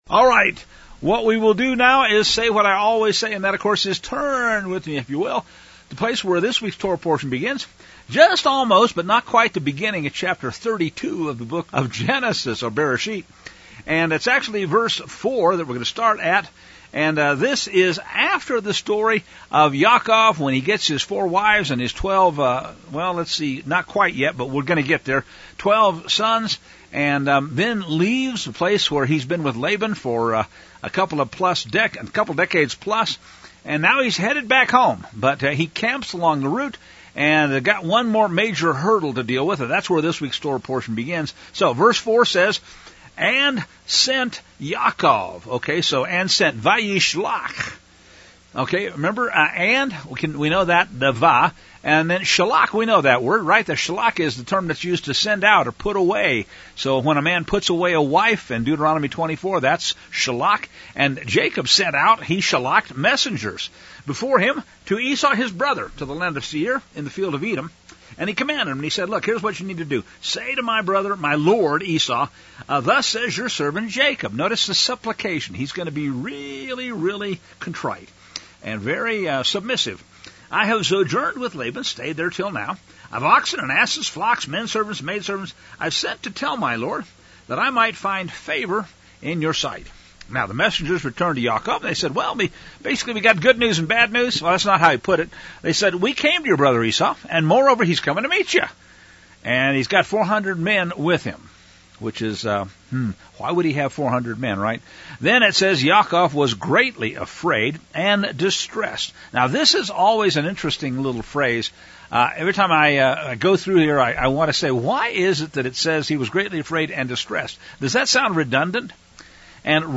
Parsha “Vayishlach” teaching from Shabbat Shalom Mesa